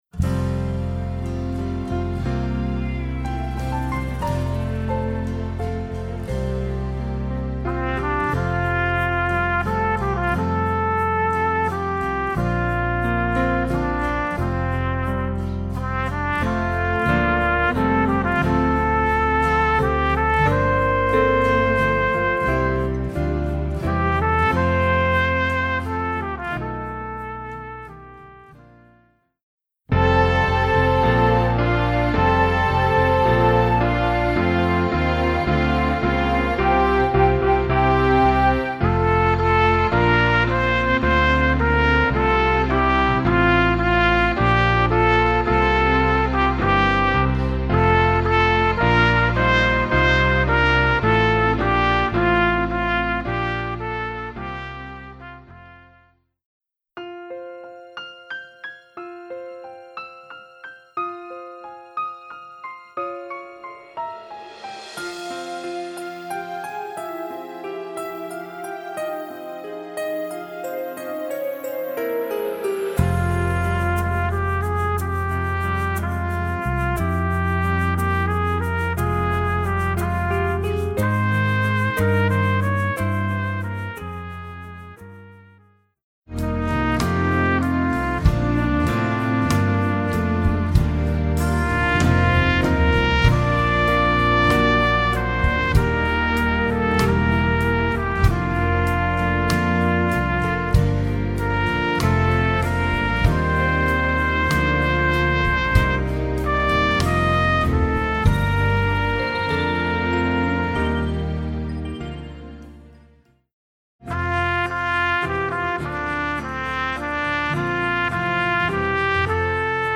トランペット+ピアノ